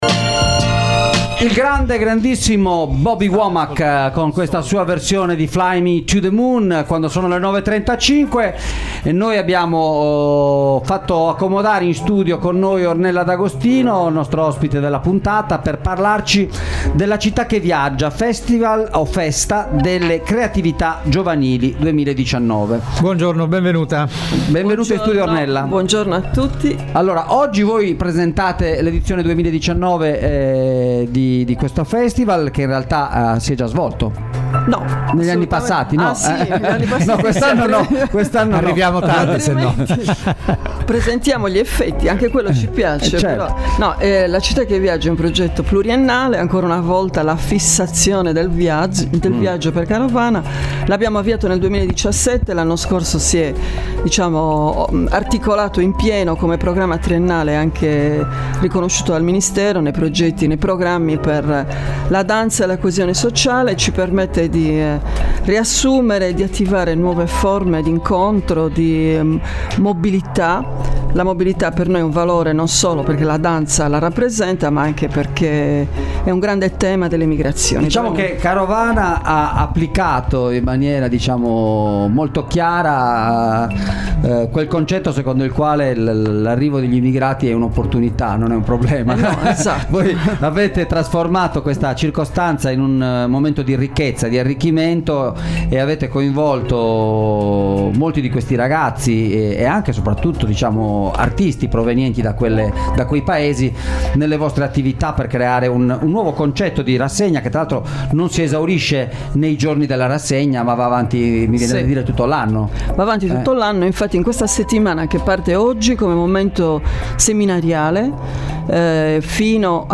La città che viaggia - intervista